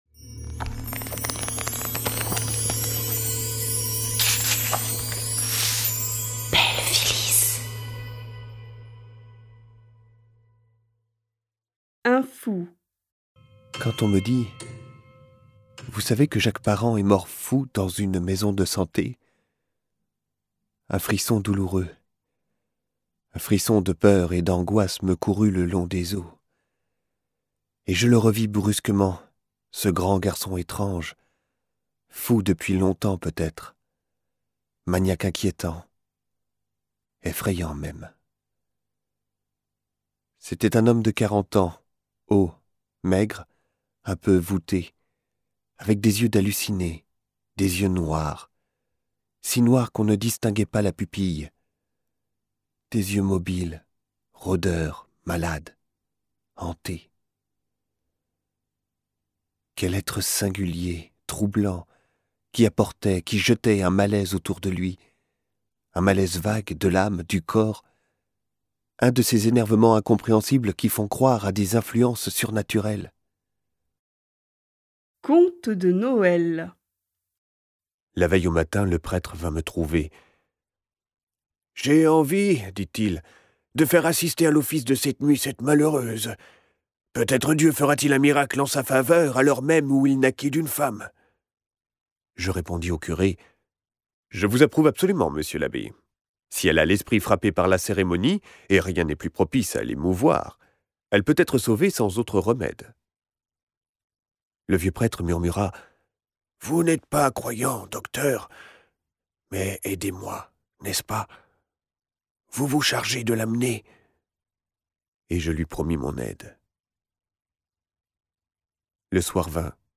Extrait gratuit - Récits fantastiques - volume 2 de Maupassant Guy de